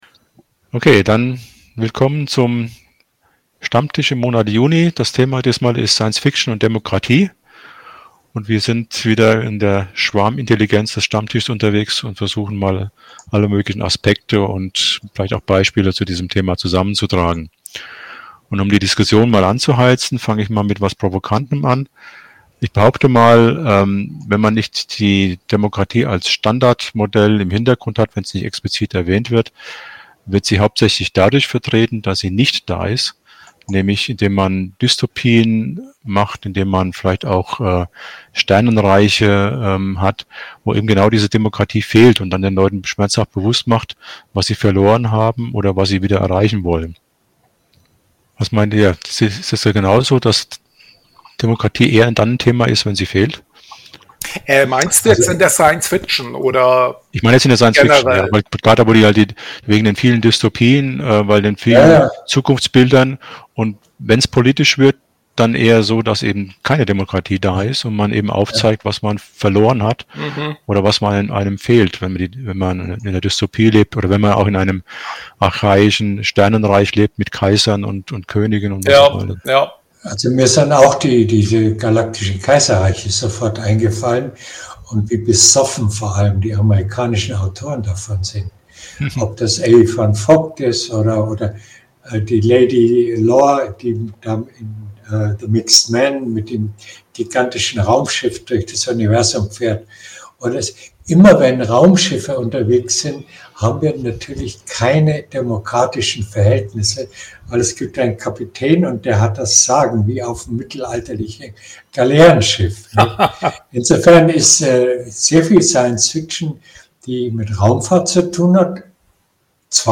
Von Zeit zu Zeit werden spezielle Themen oder Vorträge der Münchner "Phantasten" aufgezeichnet und online gestellt. Oft sind es Themen, die sich um theoretische Grundlagen des Genres handeln.